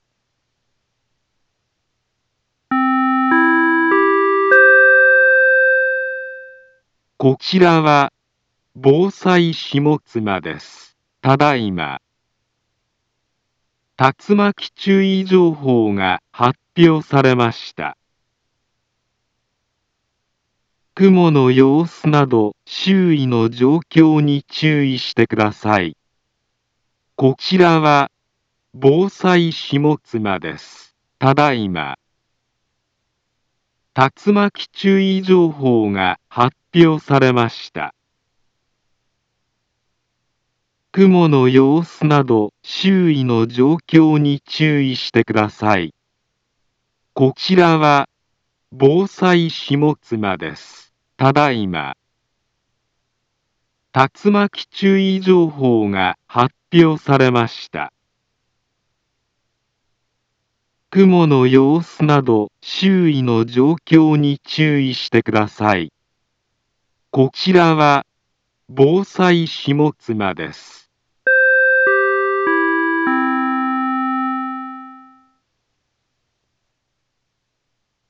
Back Home Ｊアラート情報 音声放送 再生 災害情報 カテゴリ：J-ALERT 登録日時：2023-09-08 12:19:49 インフォメーション：茨城県南部は、竜巻などの激しい突風が発生しやすい気象状況になっています。